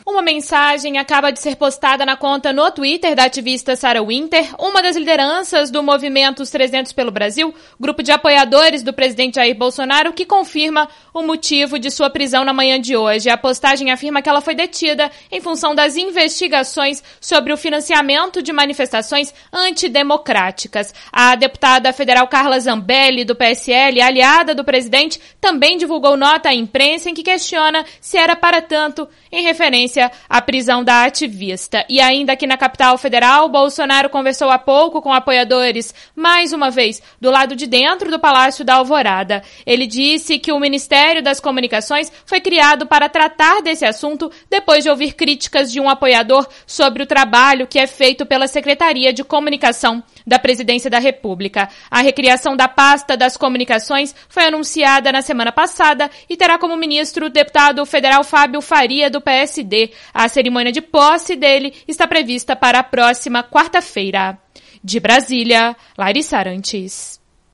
de Brasília